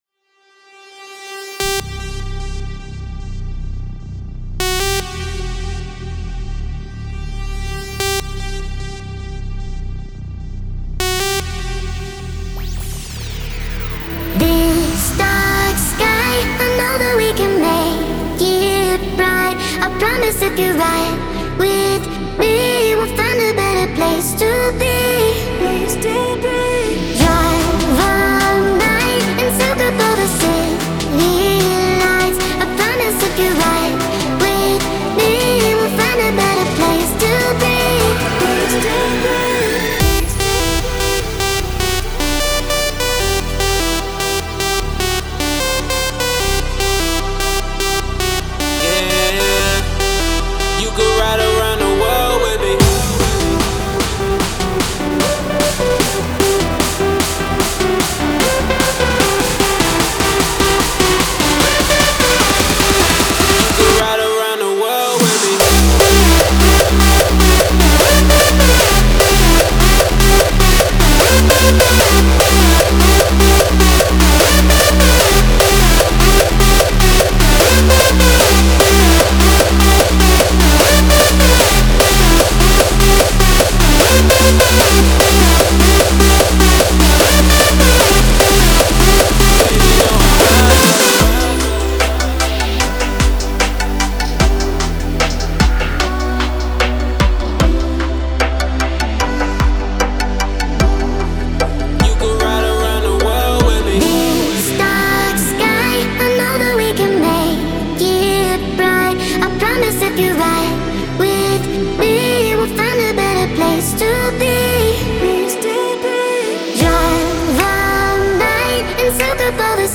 энергичная EDM-трек